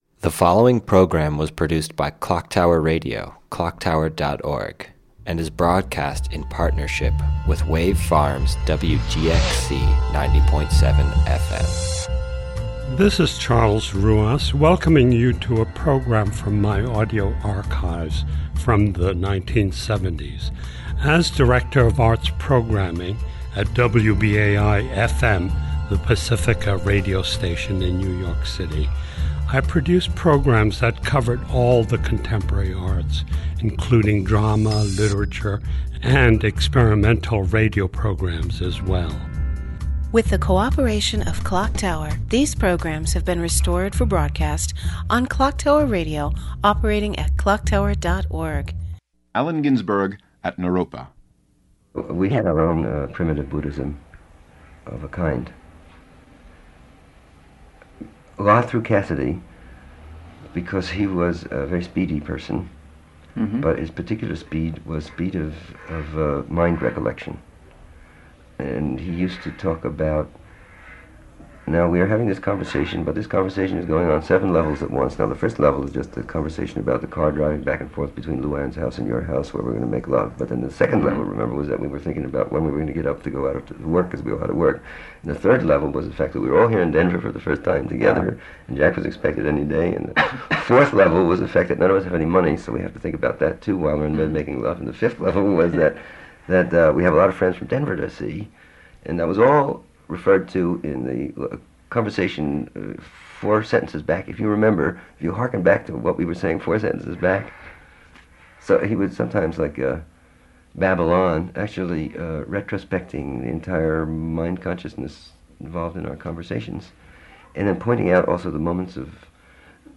From analyzing American Indian languages to experimenting with hallucinogens, the quest for enlightenment and spirituality is synonymous with The Beats. For many of those involved in the movement, the root of these ambitions rested with Buddhism. In this 1975 interview, recorded at the Naropa Institute in Boulder, Colorado; Allen Ginsberg discusses his, as well as his contemporaries's, paths towards holistic lifestyles.